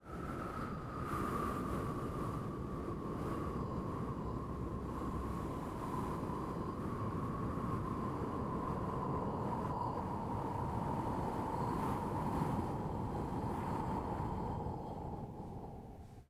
• FIRST, the WIND
[sound of wind]